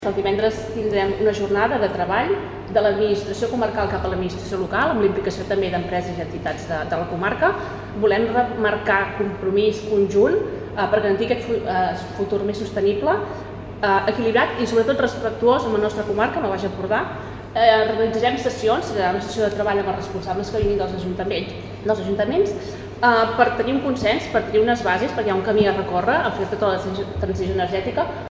La sessió finalitzarà amb una dinàmica participativa per acordar la Declaració de Sant Feliu, un document per reforçar el compromís comarcal amb la transició energètica. Ho explica la consellera del Consell Comarcal del Baix Empordà, Agnès Gasull.